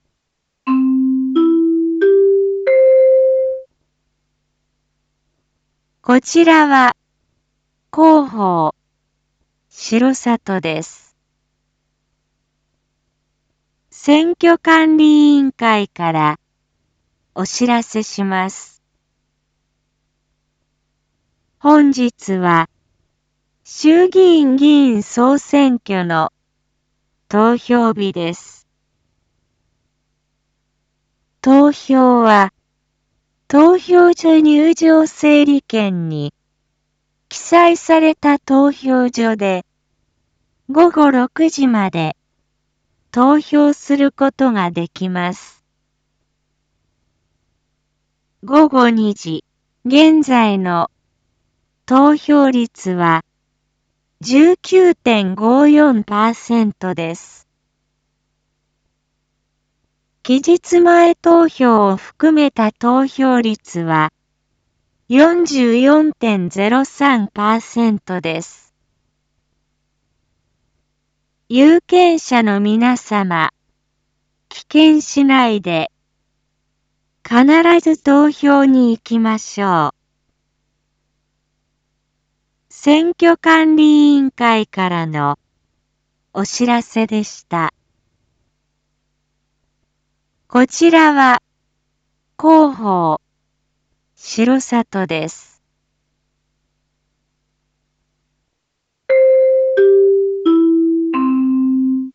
Back Home 一般放送情報 音声放送 再生 一般放送情報 登録日時：2021-10-31 14:31:43 タイトル：R3.10.31 選挙速報 14時放送 インフォメーション：こちらは広報しろさとです。